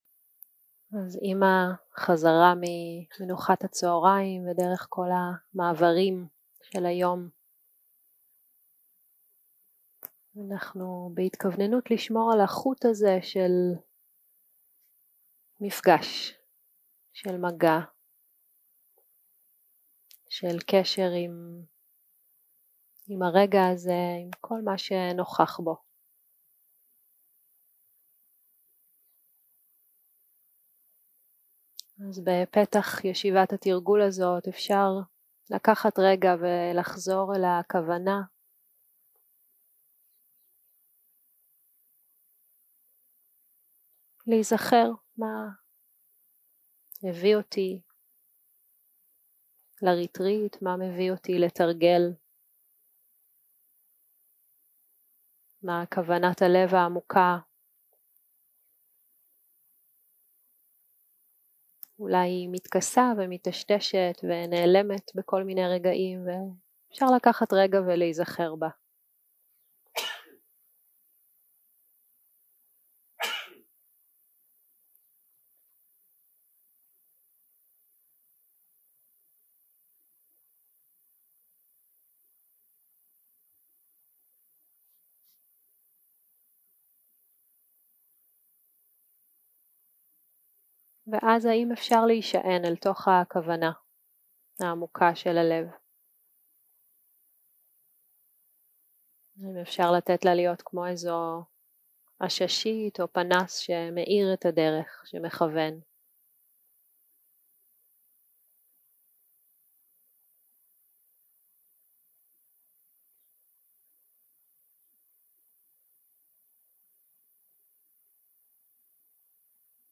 יום 2 - הקלטה 3 - צהרים - מדיטציה מונחית - עוגן בגוף ובנשימה 0:00 0:00 סוג ההקלטה: Dharma type: Guided meditation שפת ההקלטה: Dharma talk language: Hebrew